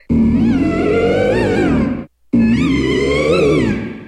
Grito de Gourgeist.ogg
Grito_de_Gourgeist.ogg.mp3